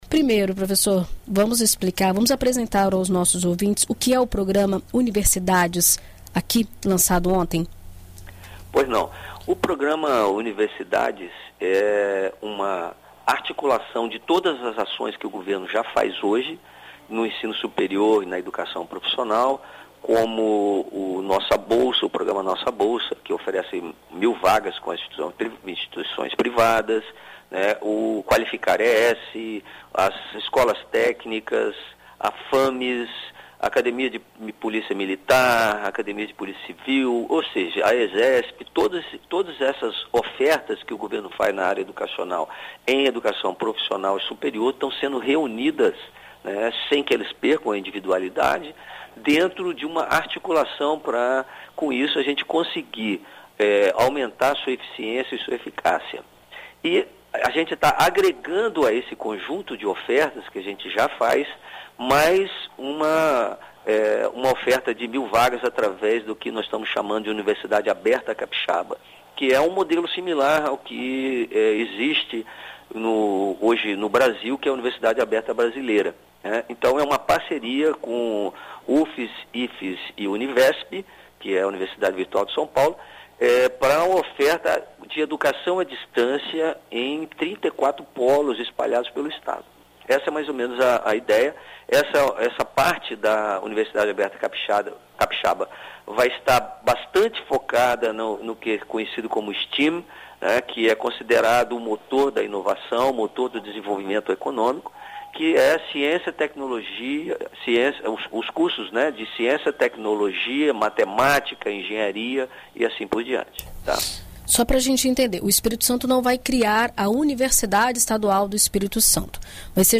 O subsecretário de Estado de Ciência, Tecnologia e Inovação da Sectides, Dênio Rebello Arantes, explica a proposta e como funcionará a seleção
ENT-SUBSECRETARIO-UNIVERSIDADES.mp3